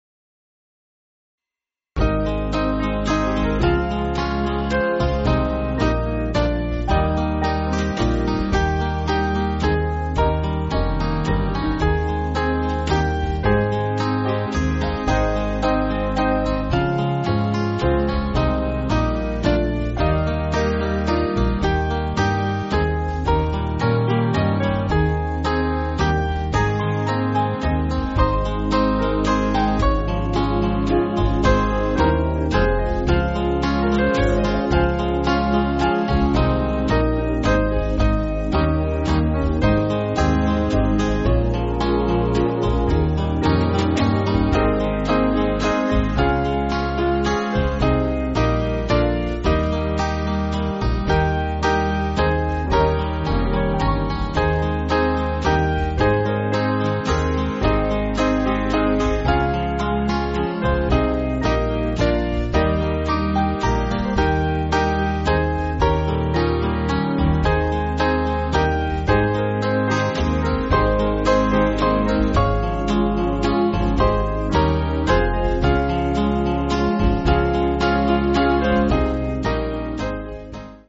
Small Band
(CM)   3/Ab